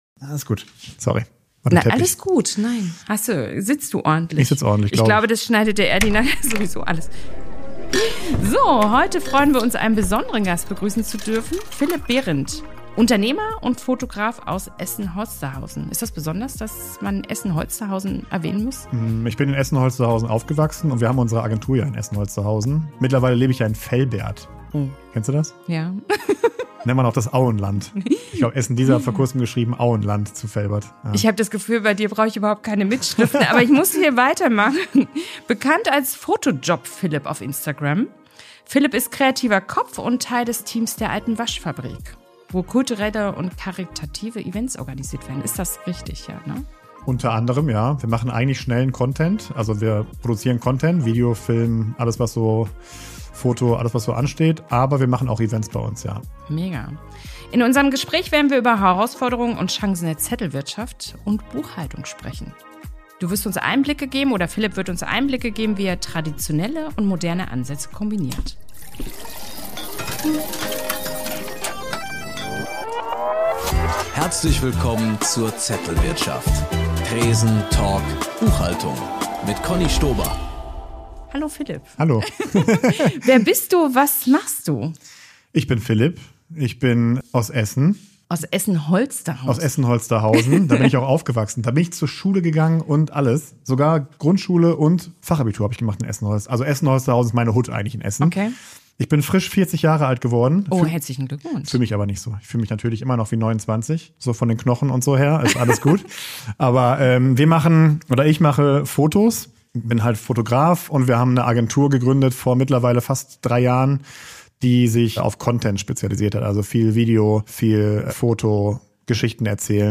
Was passiert, wenn ein leidenschaftlicher Unternehmer auf eine Frau trifft, die Buchhaltung sexy machen will? Ganz genau: Es wird laut gelacht, tief gedacht – und zwischendurch vielleicht auch mal ein Beleg gelocht.